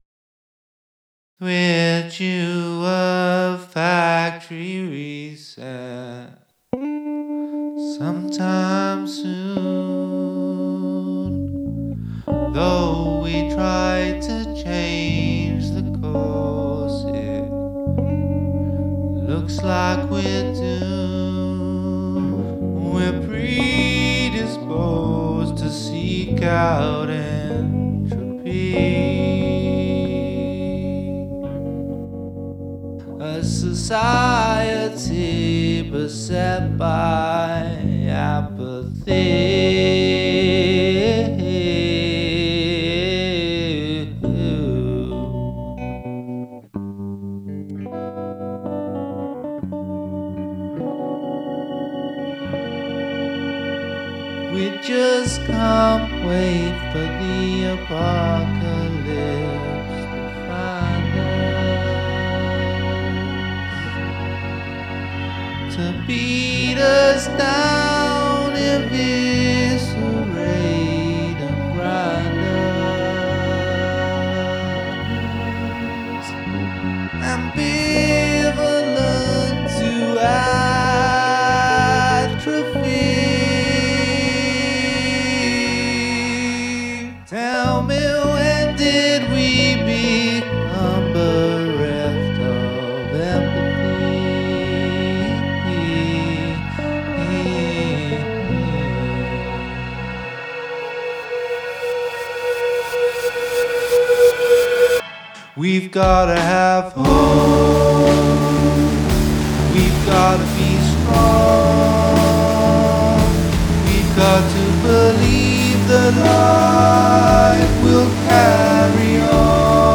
Must include at least one mood shift (musical & lyrical)